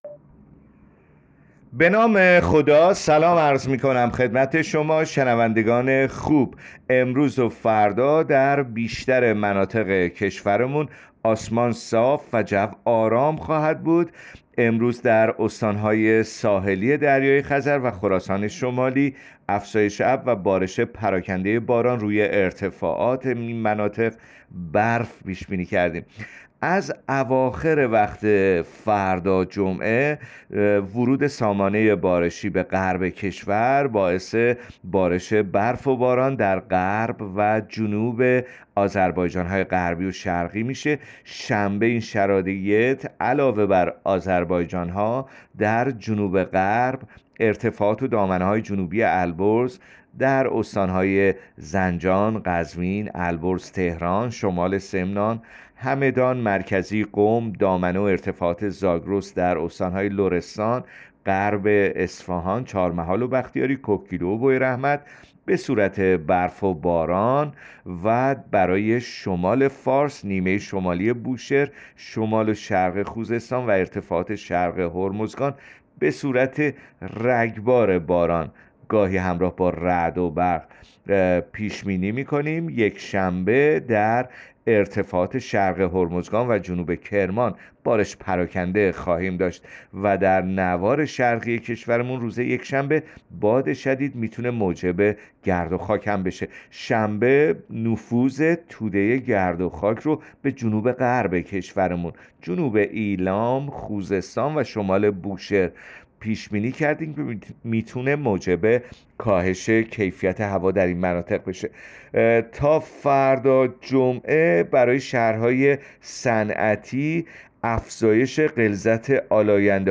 گزارش رادیو اینترنتی پایگاه‌ خبری از آخرین وضعیت آب‌وهوای ۲۹ آذر؛